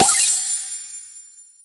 marig_hit_01.ogg